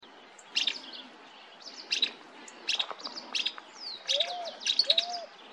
Barullero (Euscarthmus meloryphus)
Nombre en inglés: Fulvous-crowned Scrub Tyrant
Fase de la vida: Adulto
Localidad o área protegida: Reserva Natural Estricta Quebrada de las Higueritas
Condición: Silvestre
Certeza: Vocalización Grabada